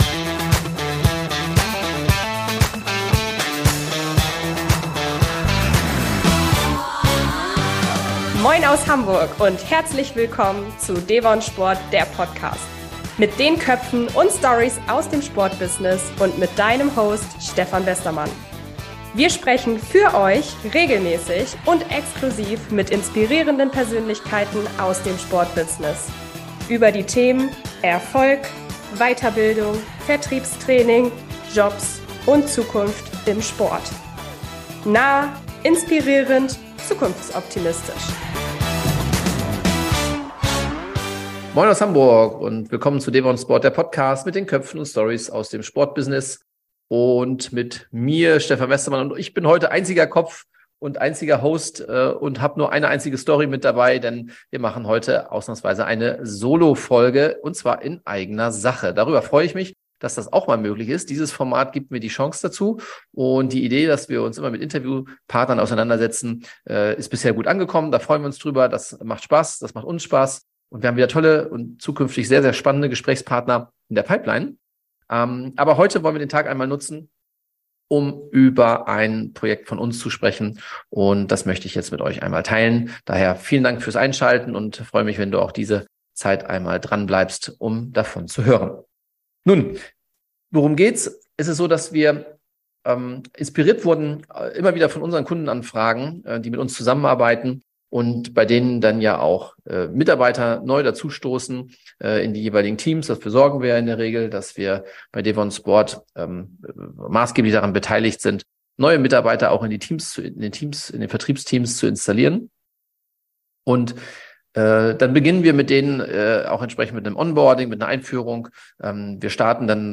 In der heutigen Solofolge stelle ich dir eines unserer Herzensprojekte bei devonSPORT vor.